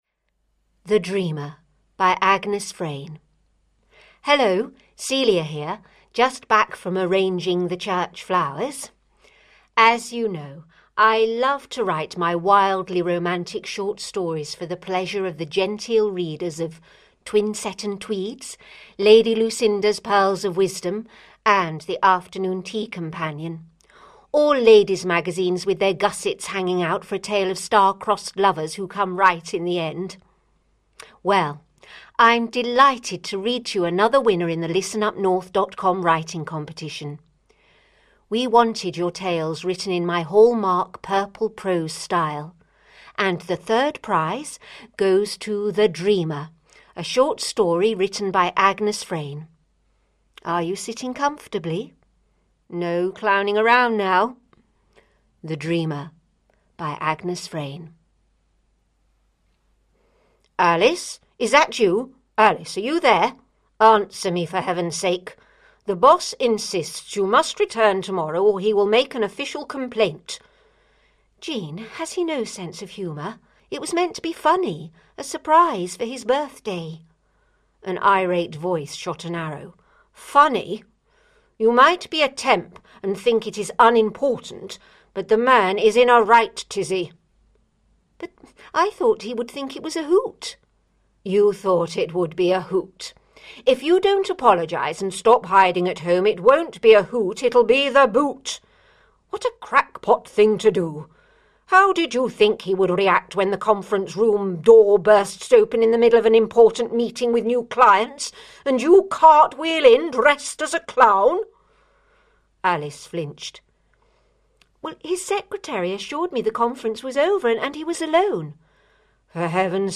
A spoof romantic short story Alice the practical joker has gone too far this time. Afraid to return to work, the boss pays her a visit...